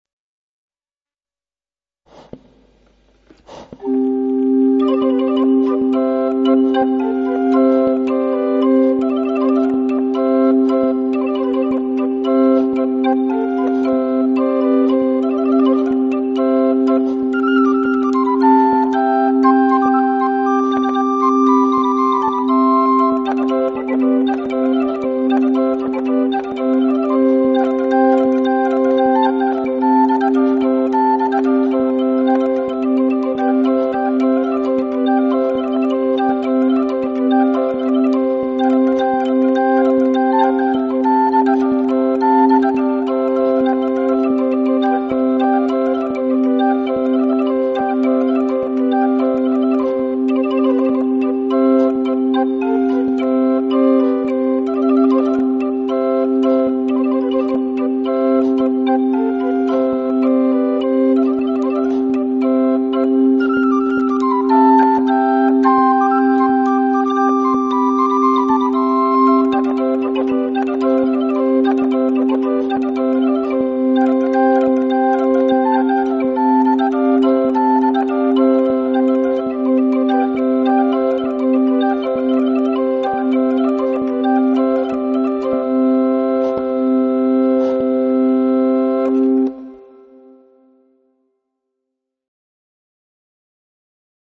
portativ.mp3